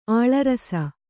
♪ āḷarasa